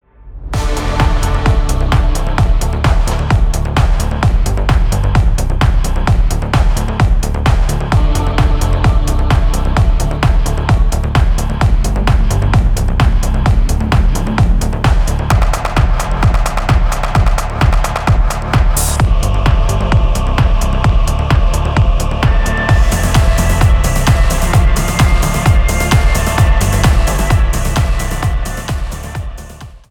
• Качество: 320, Stereo
атмосферные
без слов
приятные
Retrowave